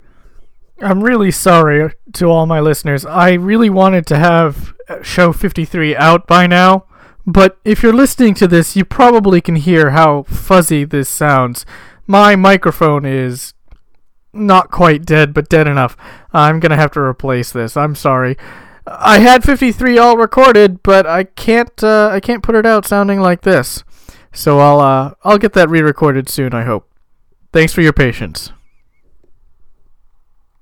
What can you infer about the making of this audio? Mic Outage wrong end of a tin can and string connection…. badmic.mp3